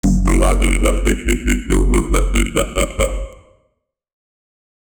Royalty-free vocal-effects sound effects
dubstep-voice-says-funked-wh4ipc5a.wav